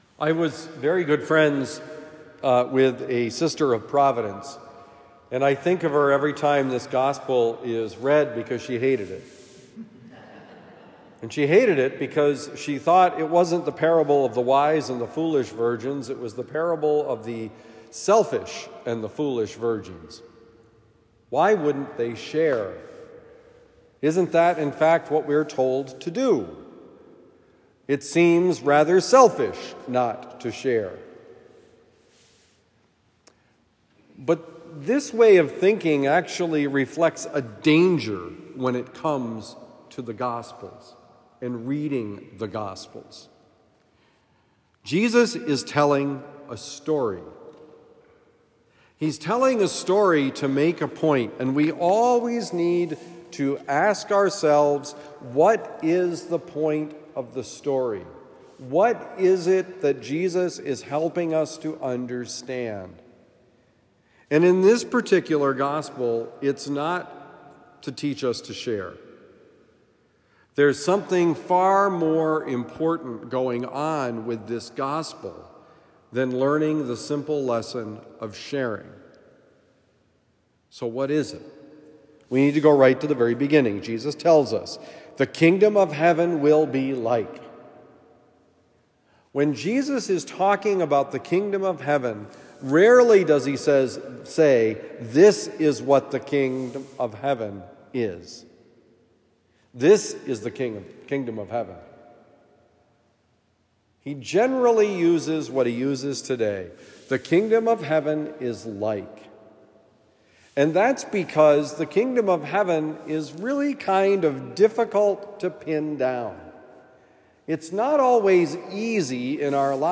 Wise or Foolish? Homily for Sunday, November 12, 2023 – The Friar